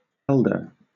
wymowa:
wymowa amerykańska?/i
amer. IPA/ˈɛldɚ/, X-SAMPA: /"Eld@`/